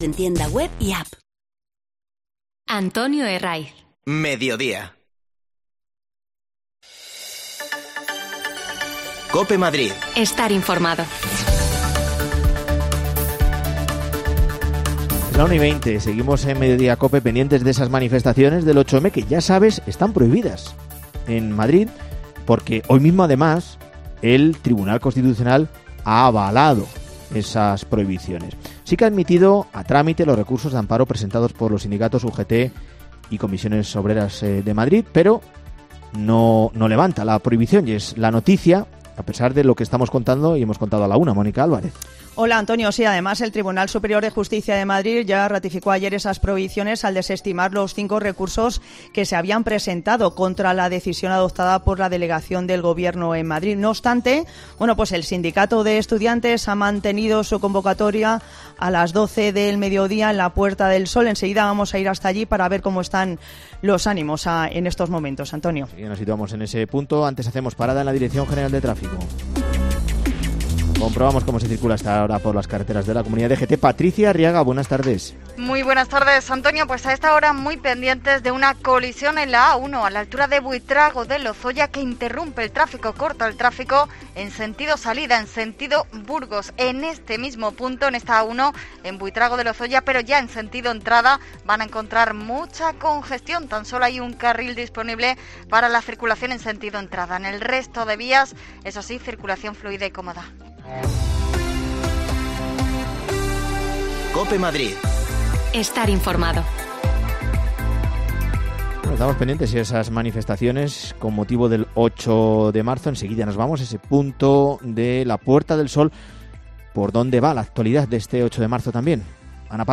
Te contamos las últimas noticias de la Comunidad de Madrid con los mejores reportajes que más te interesan y las mejores entrevistas , siempre pensando en el ciudadano madrileño.